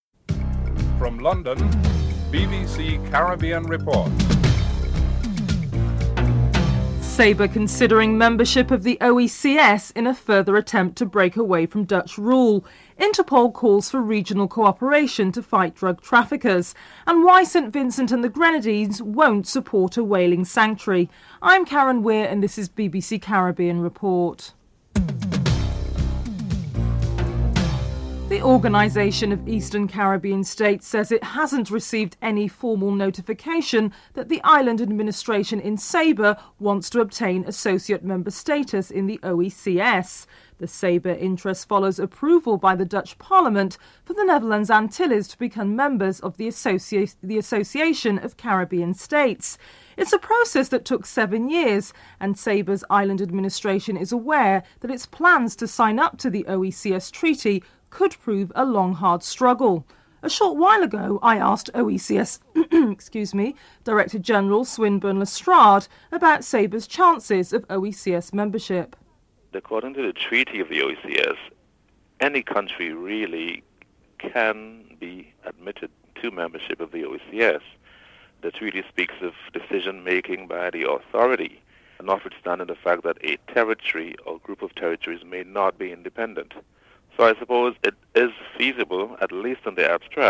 1. Headlines (00:00-00:27)
OECS Director General Swinburne Lestrade is interviewed (00:28-04:00)
Interpol Secretary General Ronald Noble is interviewed
Barbadian Ambassador June Clarke is interviewed (06:16-09:18)